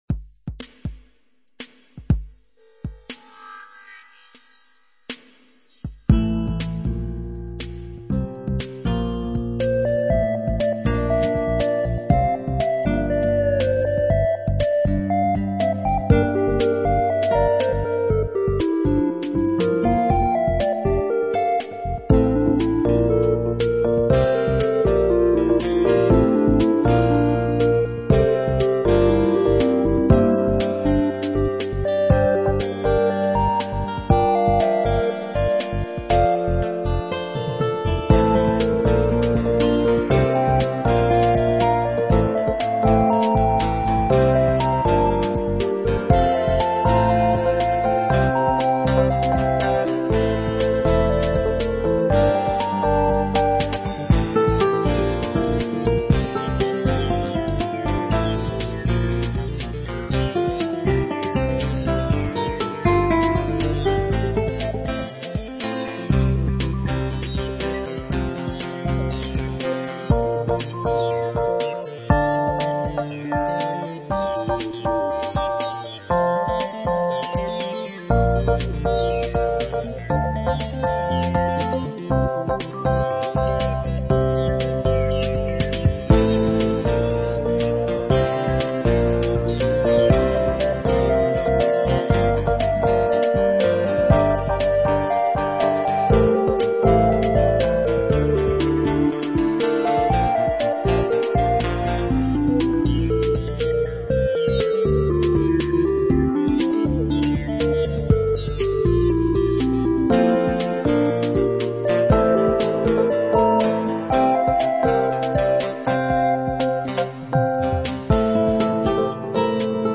Dramatic Electro Pop Soundtrack Music